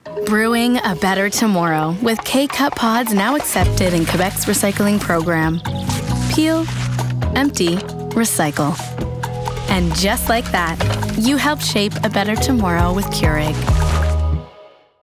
Commercial (Keurig) - EN